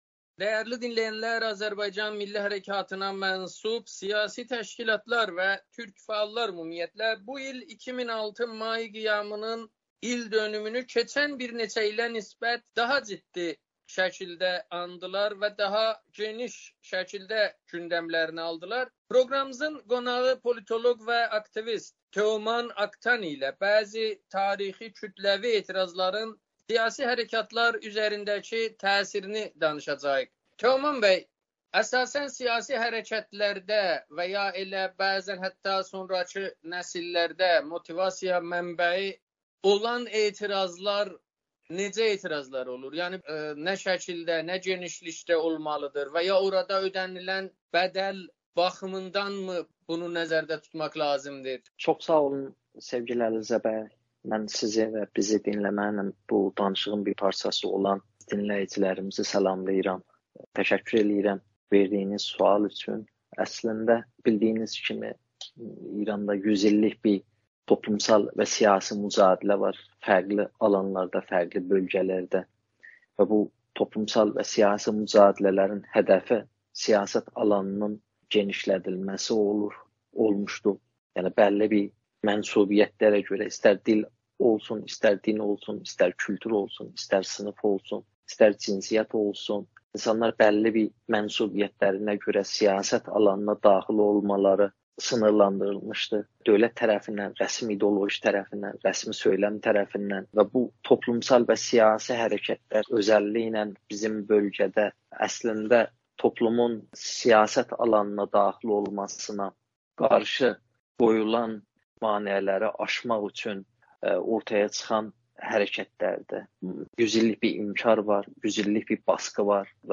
Siyasi elmlər üzrə alim və aktivist
müsahibədə